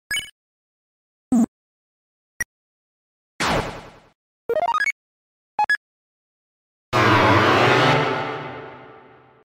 deltarune ui sounds but with sound effects free download